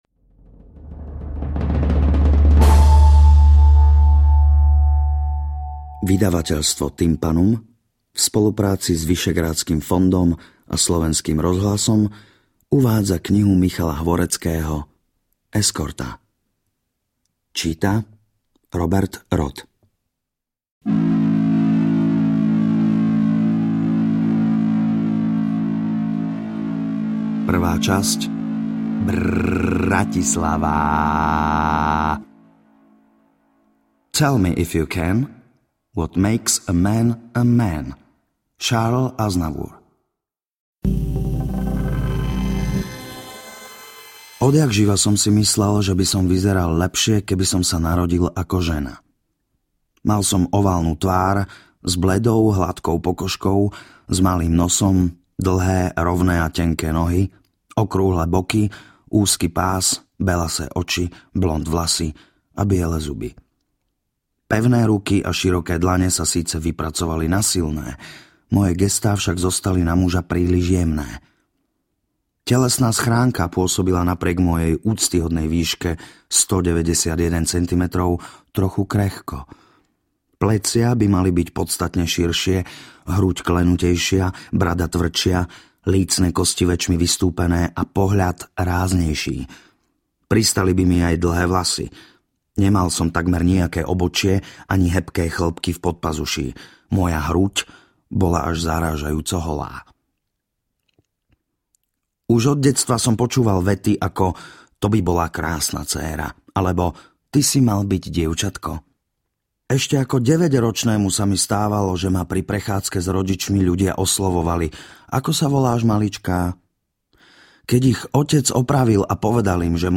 AudioKniha ke stažení, 65 x mp3, délka 8 hod. 54 min., velikost 485,7 MB, slovensky